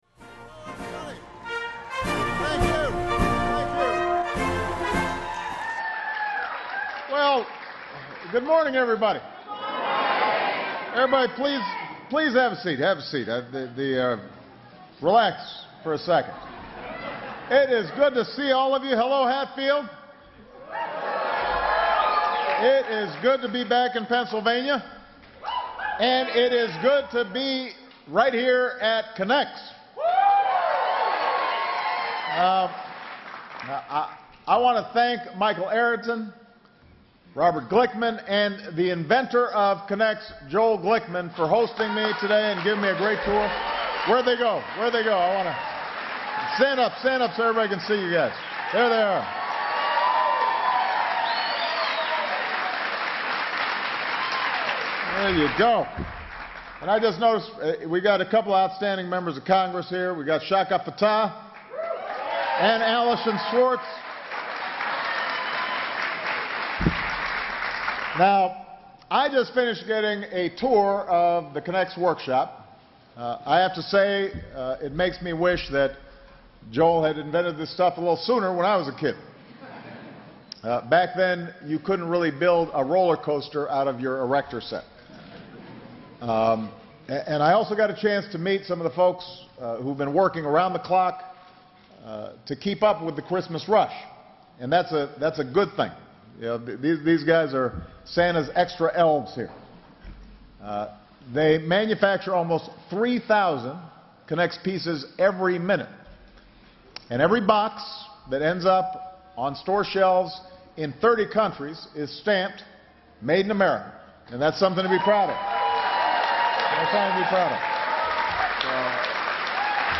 U.S. President Barack Obama gives a speech at Rodon Group Manufacturing in Montgomery County, PA
Broadcast on C-SPAN, Nov. 30, 2012.